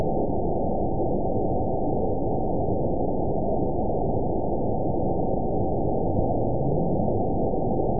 event 922647 date 02/23/25 time 23:00:44 GMT (3 months, 3 weeks ago) score 9.56 location TSS-AB01 detected by nrw target species NRW annotations +NRW Spectrogram: Frequency (kHz) vs. Time (s) audio not available .wav